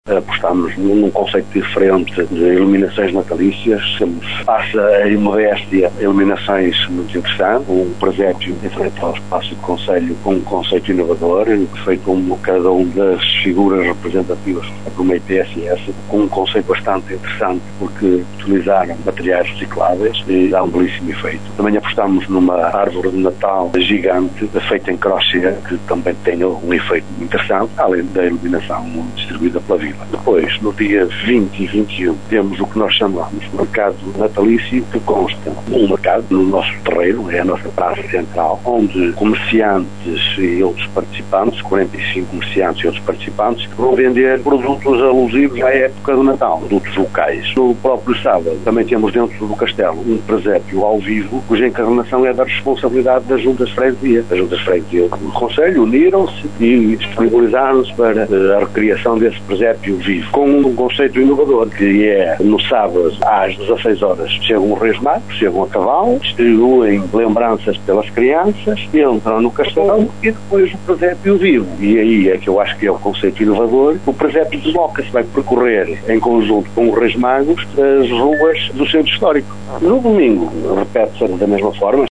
É desta forma que o presidente da Câmara de Vila Nova de Cerveira, Fernando Nogueira, define a animação de Natal do município.